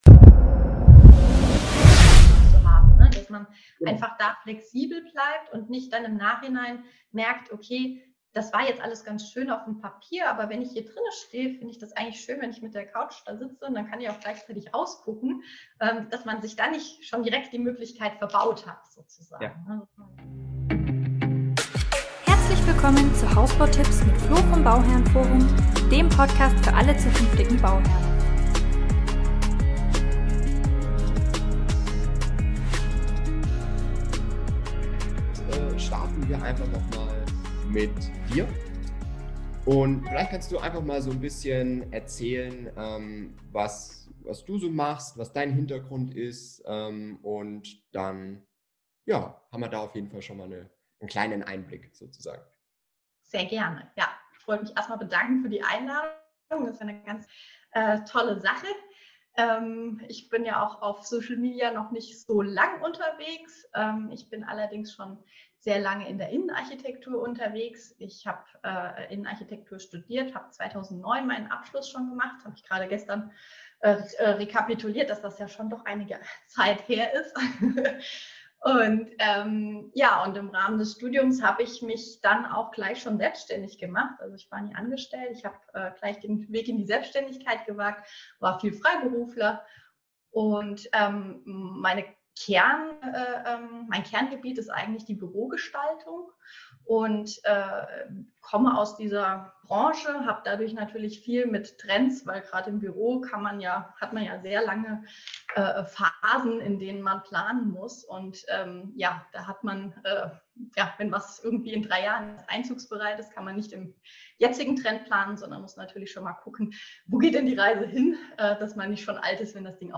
Aktuelle Trends in der Raumplanung | Interview